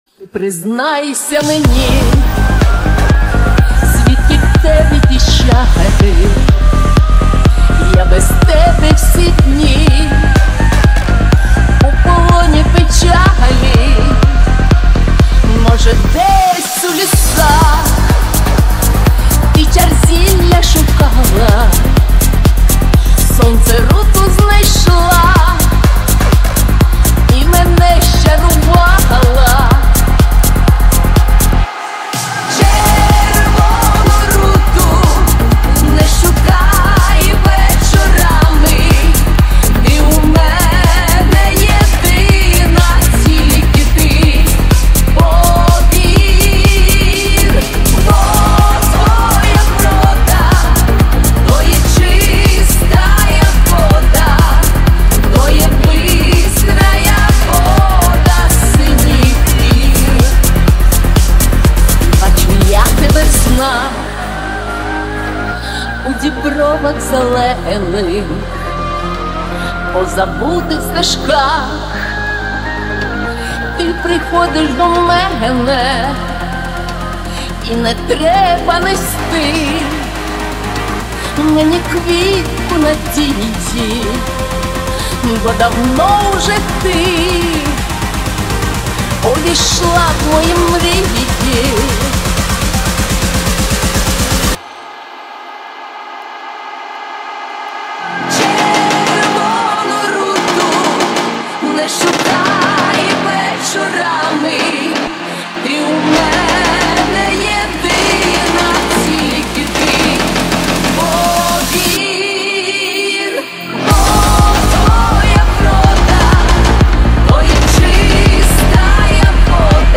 • Жанр: Pop, Electronic, Dance